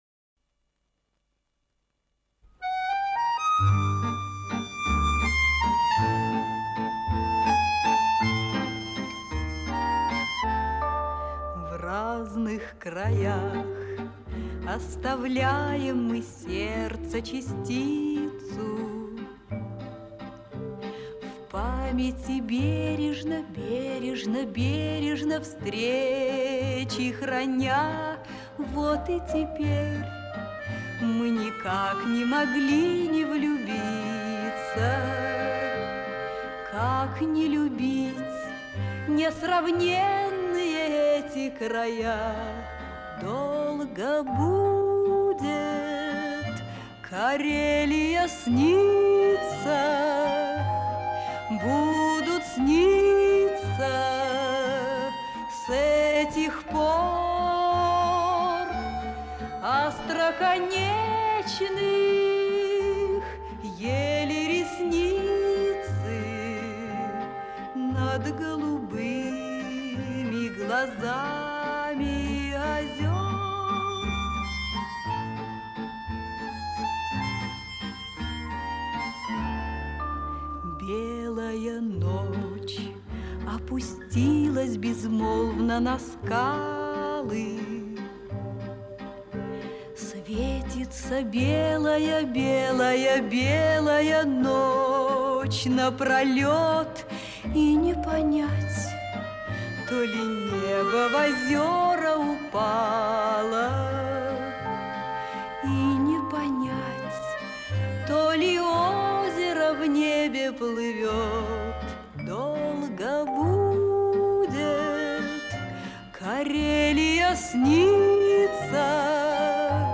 Смущение души сменяется покоем.